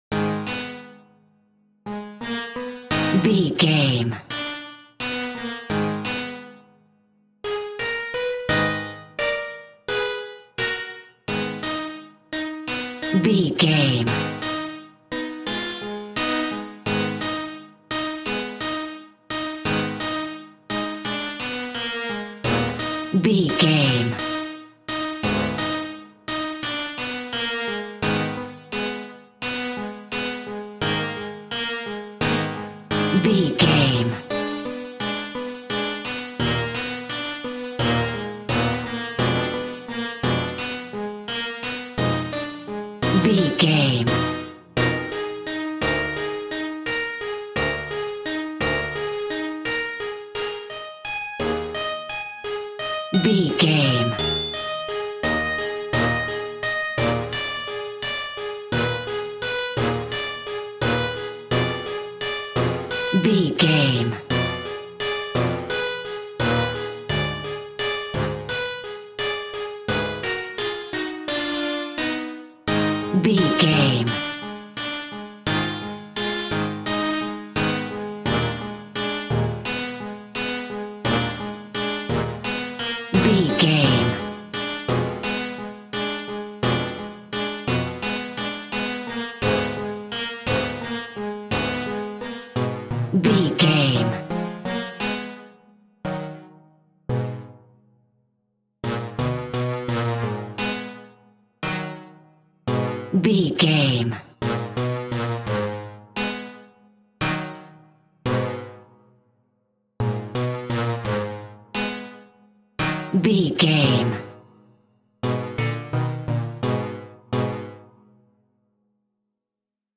Thriller
Aeolian/Minor
G#
scary
ominous
dark
haunting
eerie
synthesiser